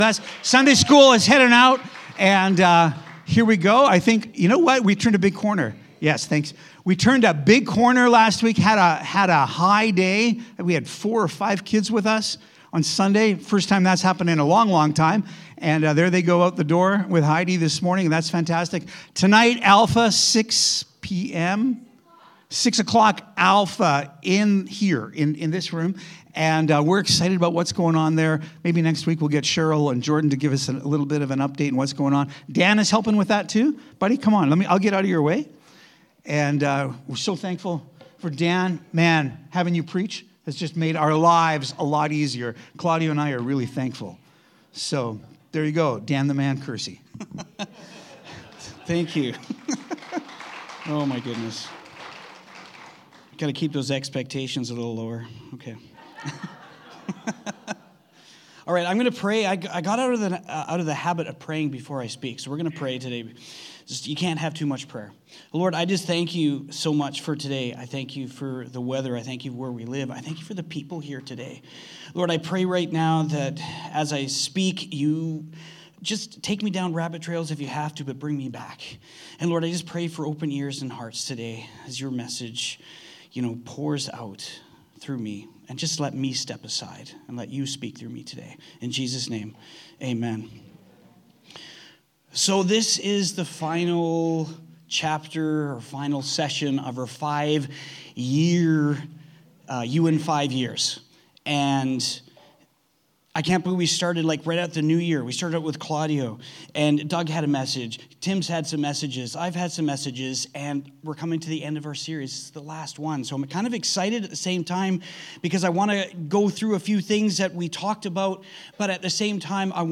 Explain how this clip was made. Please note - the audio from the video played during he message has been deleted due to possible copyright concerns